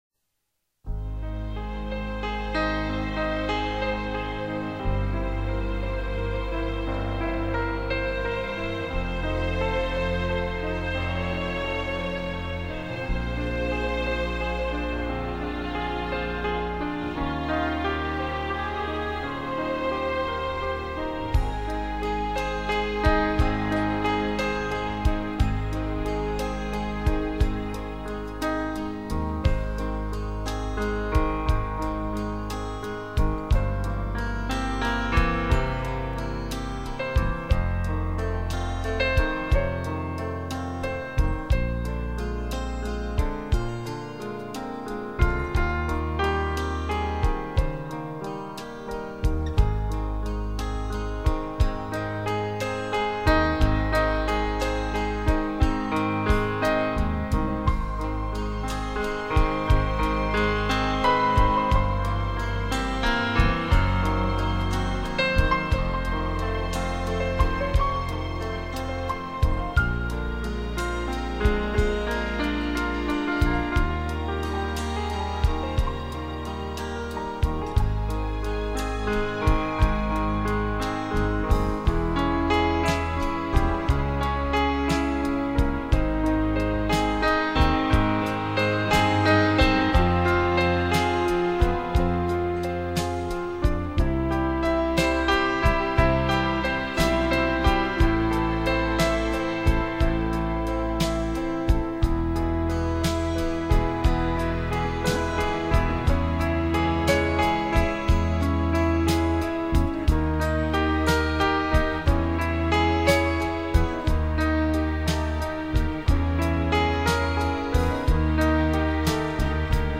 手指微妙触及琴键所散发出的音符，充满罗曼蒂克式的醉人芳香将你的情思带入神话中的伊甸园。